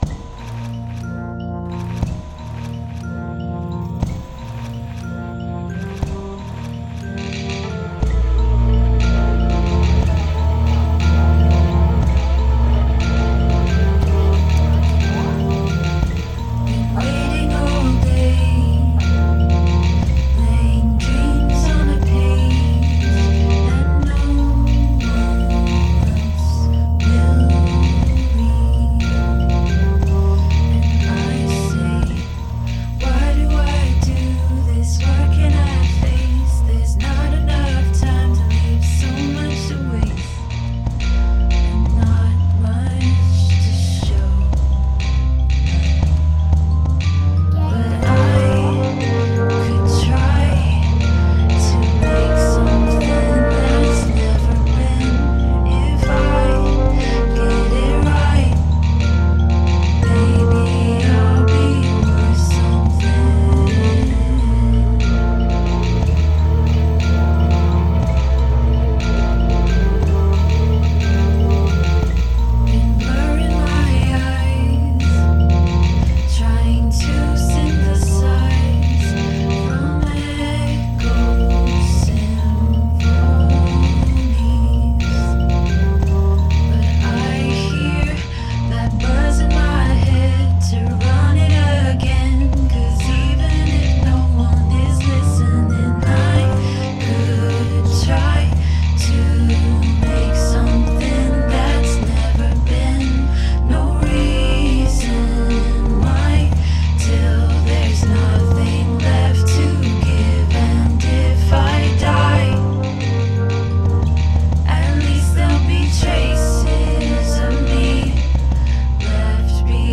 One sample of air being blown in the beer bottle
public domain sample of a car horn
Two different microwave beeping sounds
Snare: keys being slapped on a countertop
Kick: stomping through my hallway
Chill ambience: cicadas and crickets courtesy of Houston TX
Full of mood, great pace, plenty of space in the mix.
When it goes big, it goes real big, great decision.